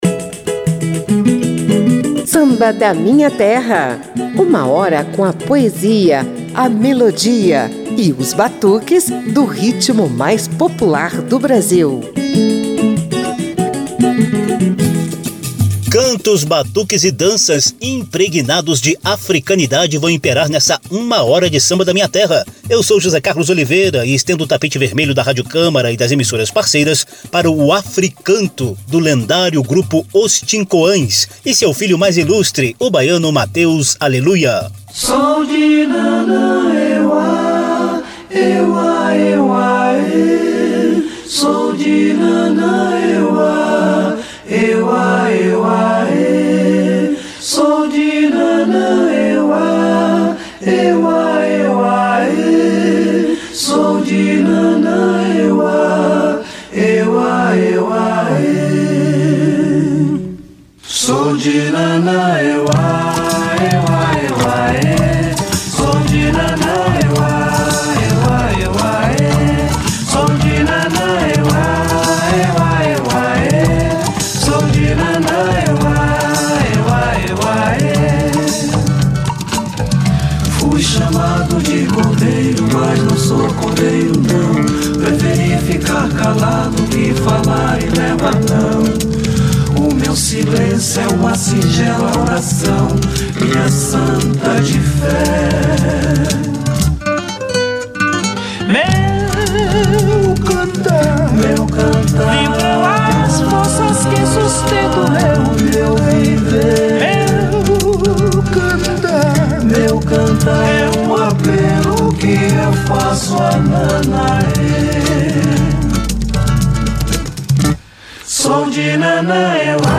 O som do grupo vai muito além do samba de roda, típico da região.